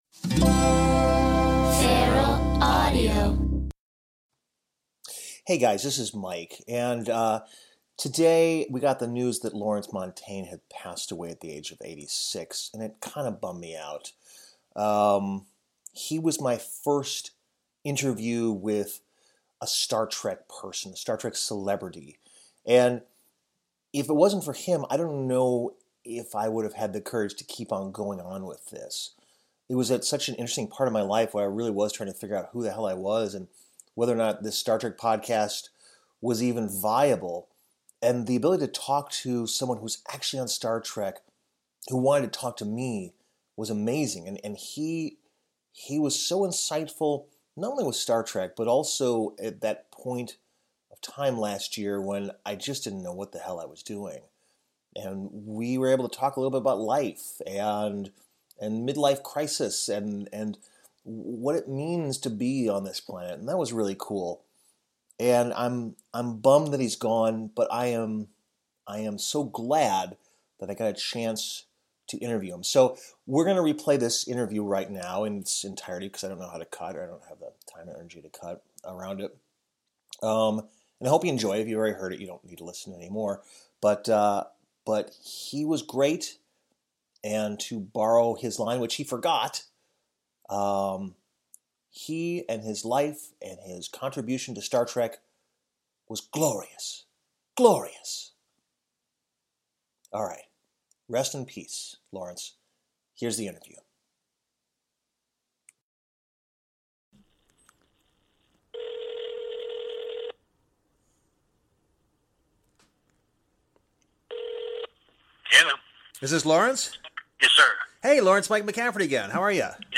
Celebrating Lawrence: A Replay of Lawrence Montaigne's Interview